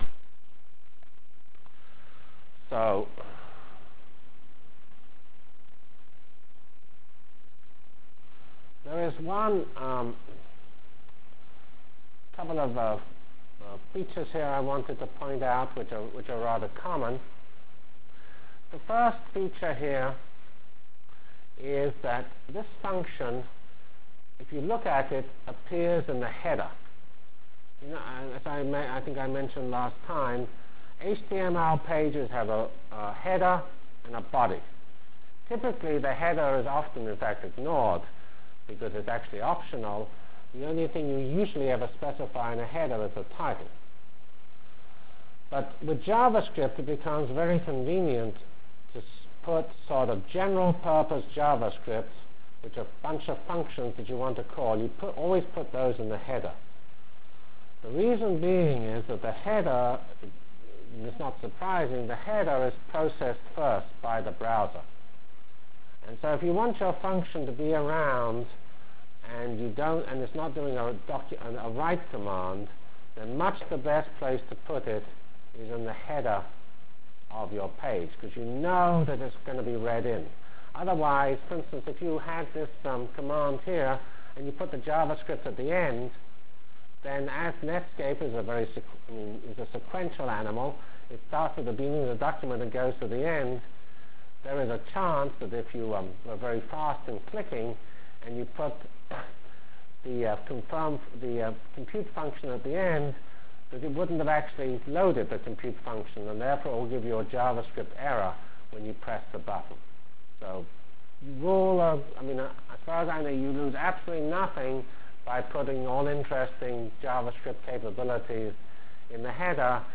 From Feb 12 Delivered Lecture for Course CPS616 -- Basic JavaScript Functionalities and Examples CPS616 spring 1997 -- Feb 12 1997.